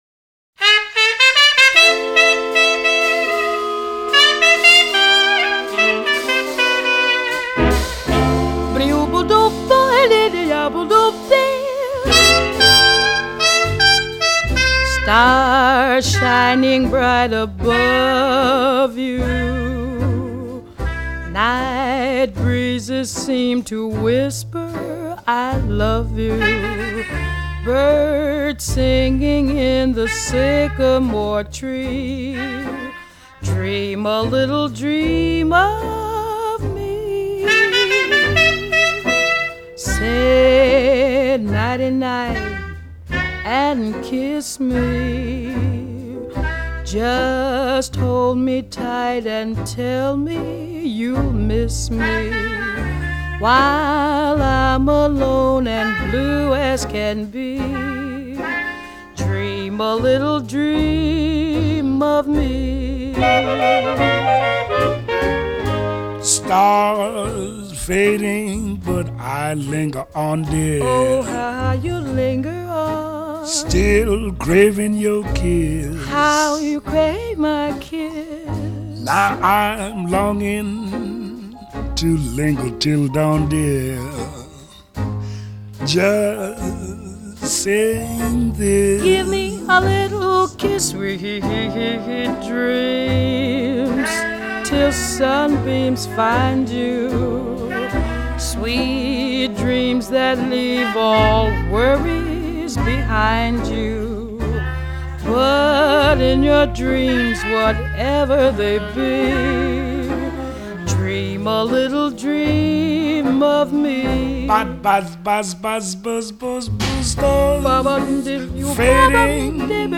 Small Band
• BALLAD (JAZZ)
• VOCAL (JAZZ)
• Trumpet
• Clarinet
• Tenor Sax
• Piano
• Guitar
• Bass
• Drums